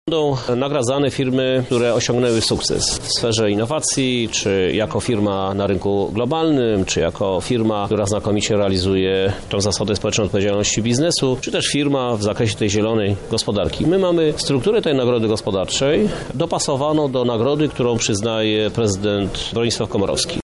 W piątek w hali lubelskich targów odbyła się gala przedsiębiorczości.
Nagrody wręczał prezydent Lublina, Krzysztof Żuk.